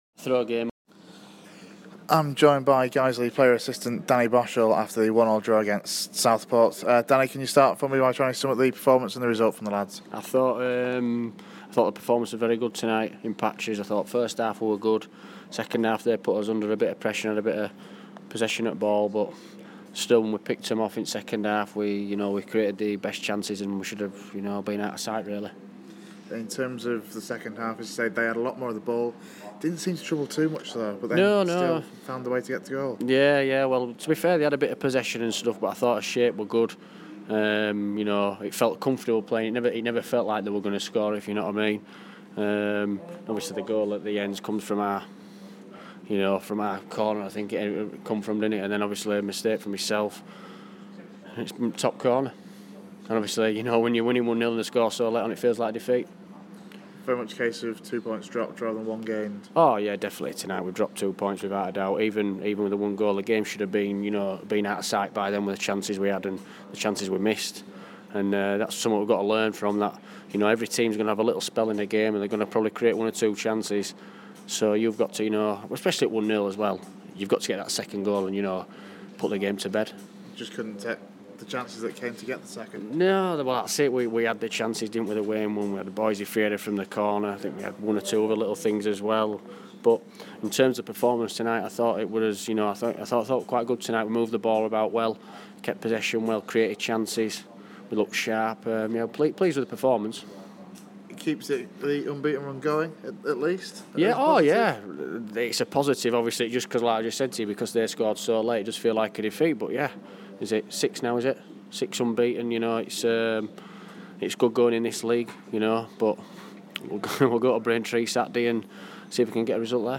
speaks after the 1-1 draw against Southport.